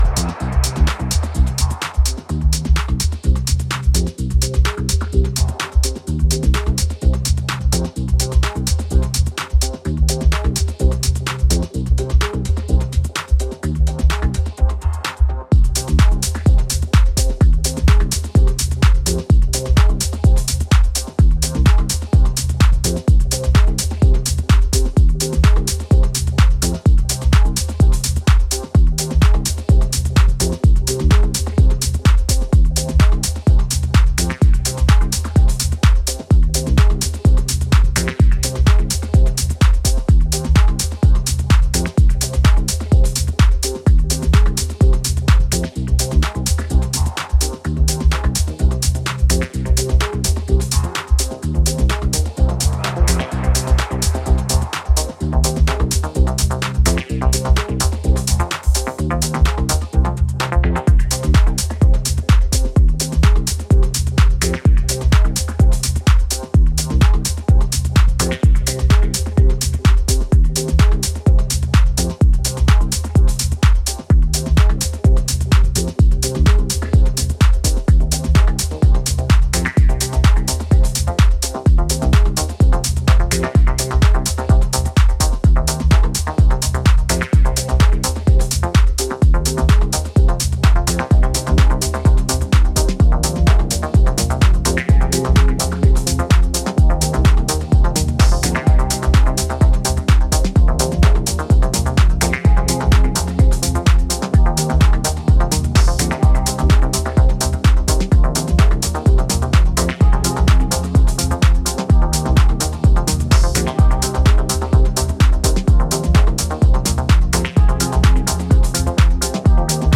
Reshaping the 70s, 80s, & 90s with the sounds of now.